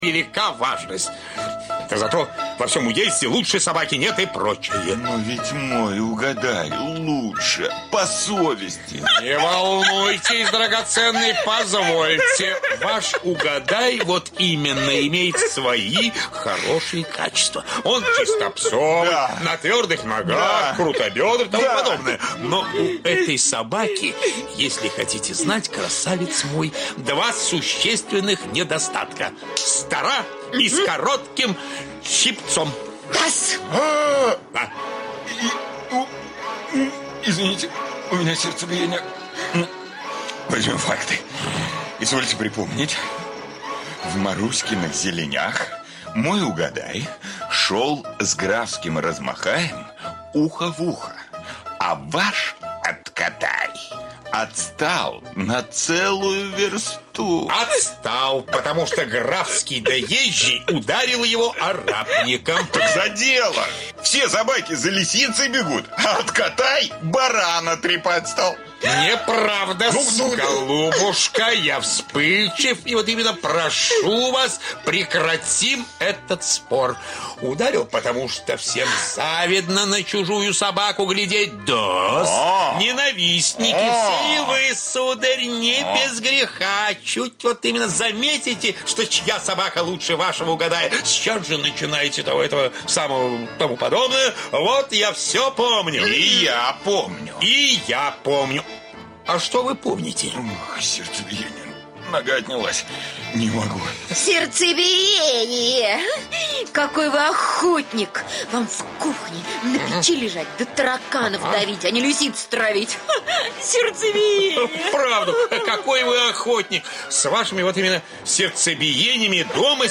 "Литературные чтения" на "Радио "России"